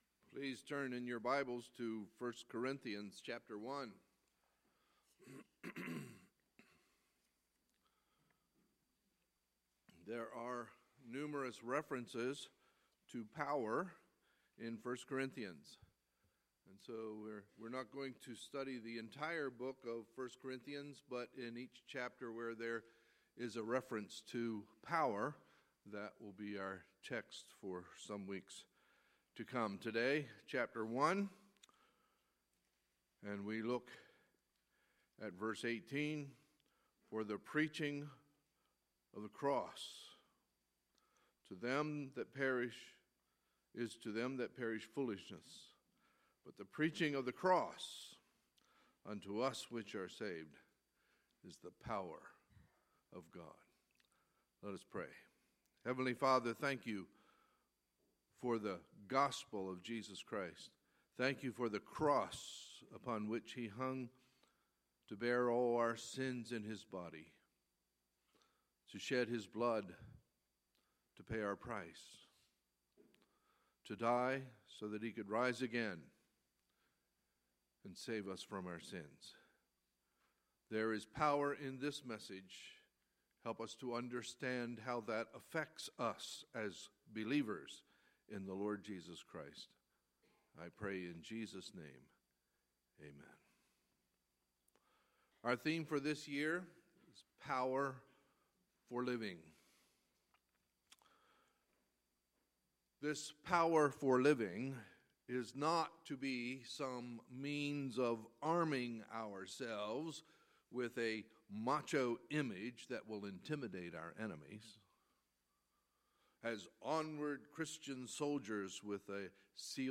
Sunday, January 22, 2017 – Sunday Morning Service